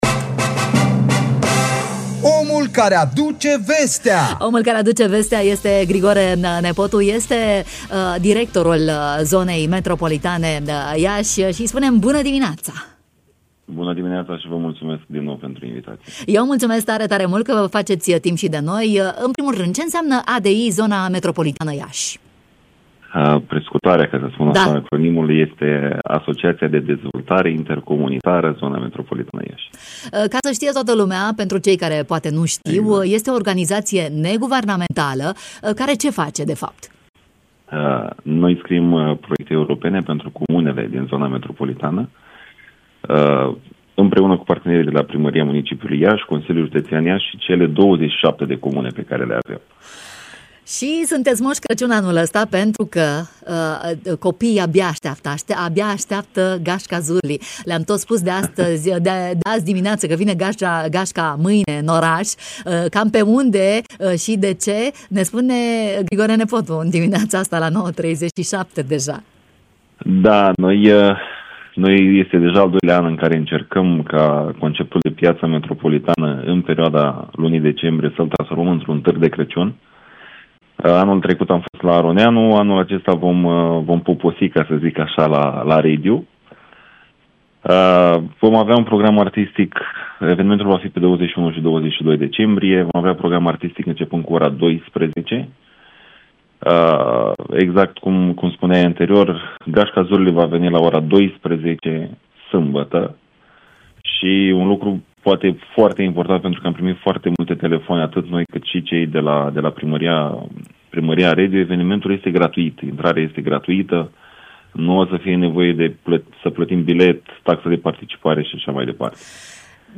ne-a invitat la distracție și cumpărături, în matinal